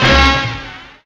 TRUMP SLUR.wav